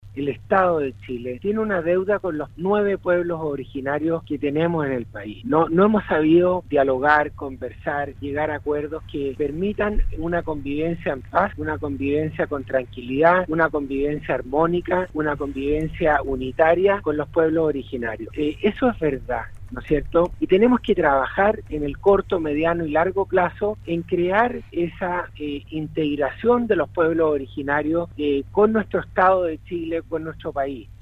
El Secretario de Estado, en diálogo con Radio SAGO, en torno al clima de violencia rural, reconoció que existe una deuda histórica del Estado con 9 pueblos originarios y no se ha sabido conversar para alcanzar la paz y la integración de éstos, señaló Antonio Walker.